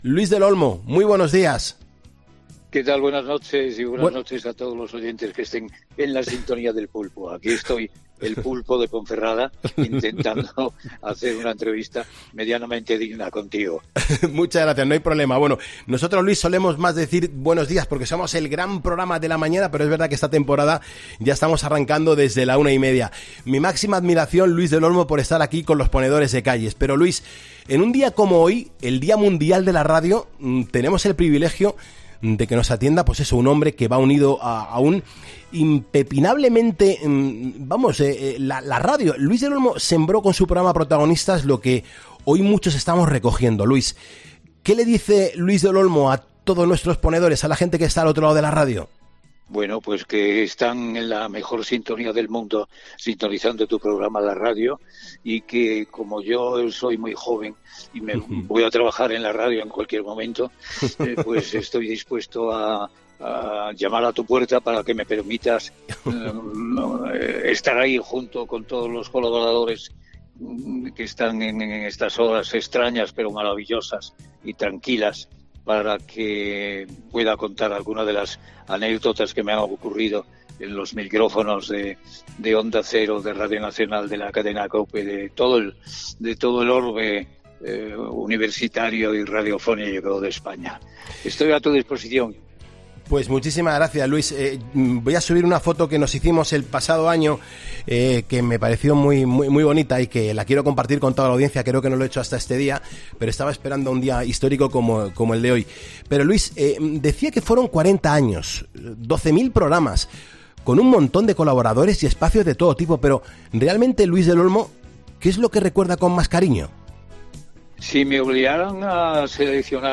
La gran leyenda de la radio española ha querido celebrar el Día Mundial de la Radio en Poniendo las Calles junto a Carlos Moreno "El Pulpo"